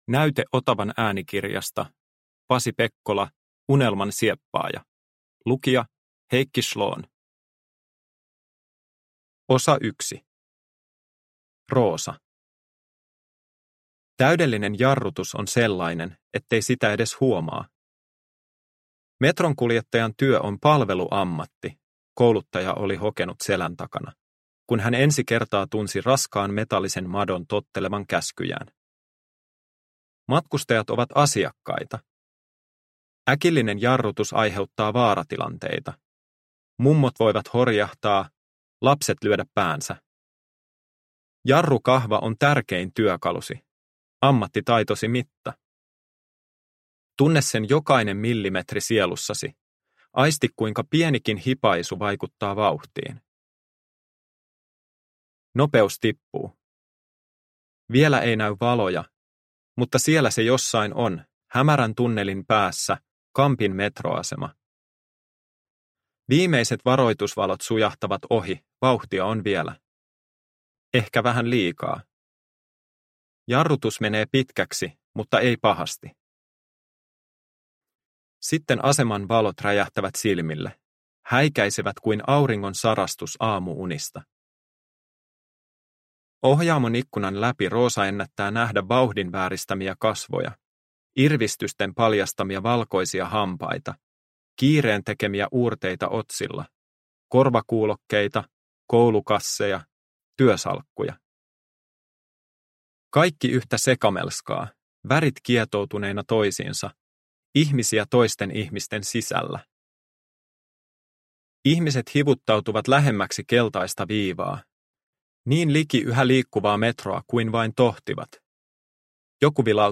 Unelmansieppaaja – Ljudbok – Laddas ner